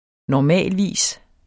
Udtale [ nɒˈmæˀlˌviˀs ]